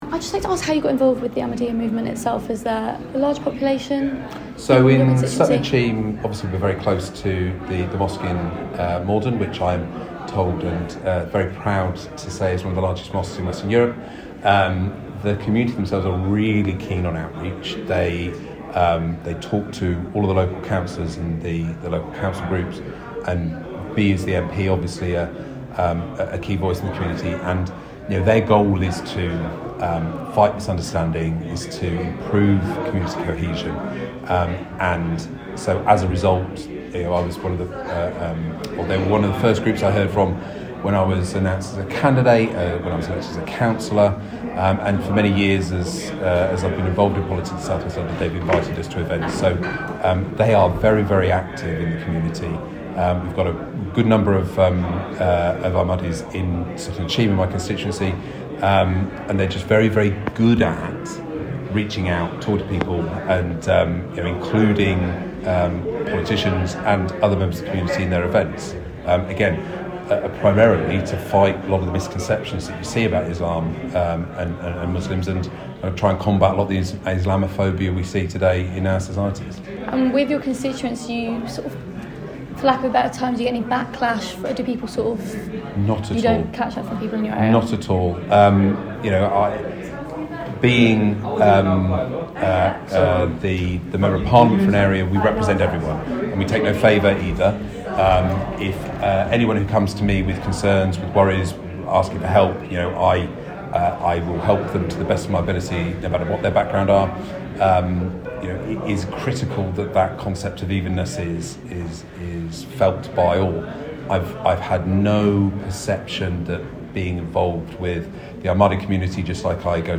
speaks with Sutton and Cheam MP, Luke Taylor.